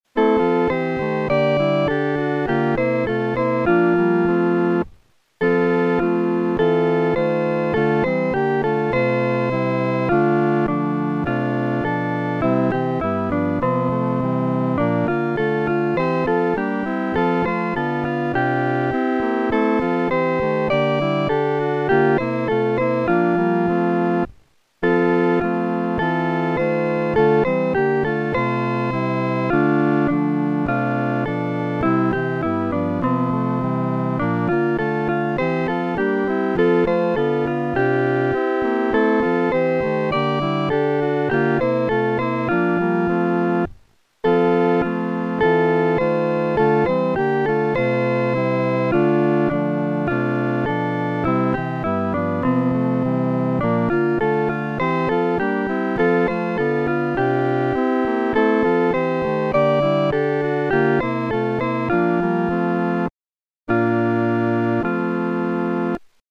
男低
本首圣诗由网上圣诗班录制